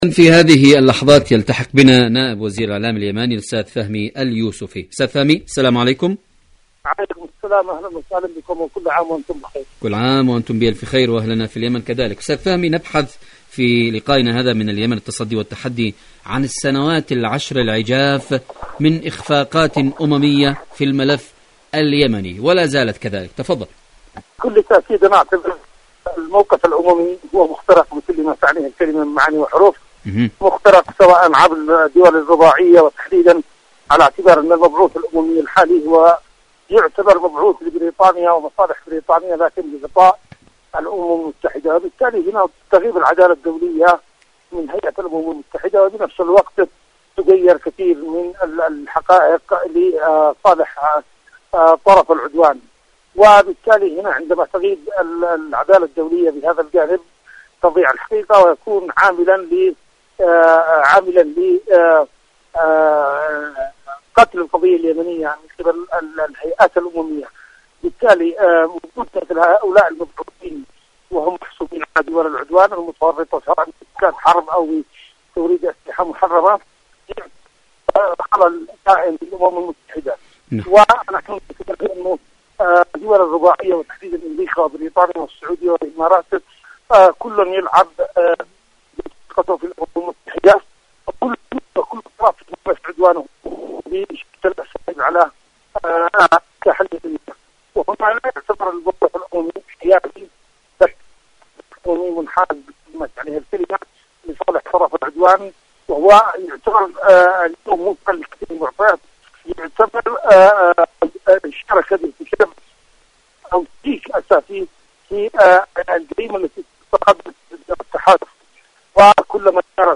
إذاعة طهران-اليمن التصدي والتحدي: مقابلة إذاعية مع فهمي اليوسفي نائب وزير الإعلام اليماني من اليمن حول موضوع 10 سنوات من الإخفاق الأممي في اليمن.